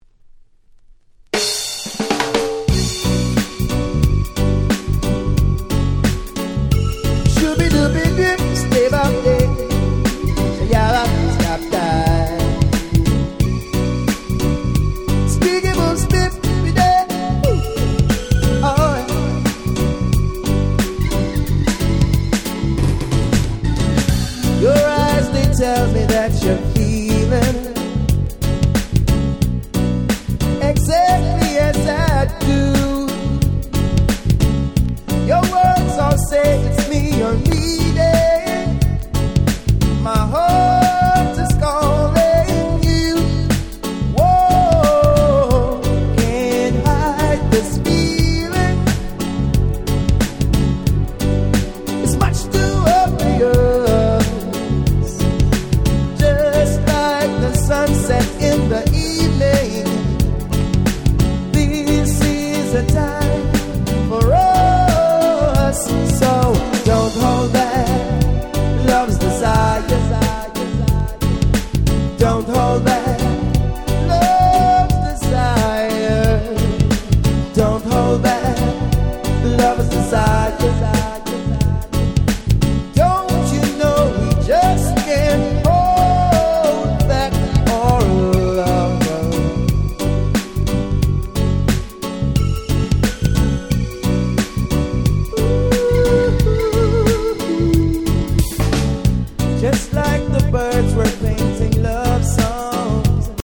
94' Very Nice Reggae / R&B !!
詳細不明ながら日本企画のナイスな歌モノレゲエ！！
両面共にGroovyで最高！！
90's lovers ラバーズレゲエ